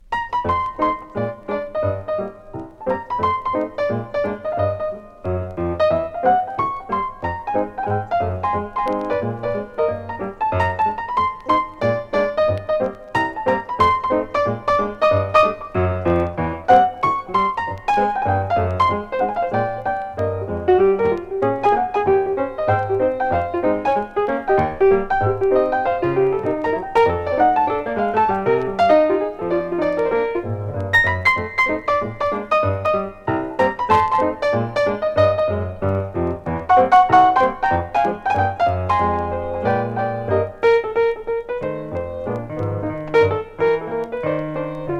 Jazz, Swing, Vocal　USA　12inchレコード　33rpm　Stereo